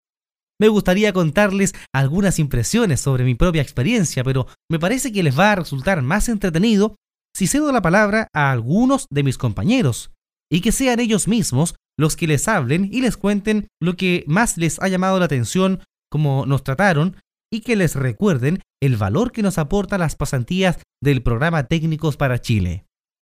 locutor chileno.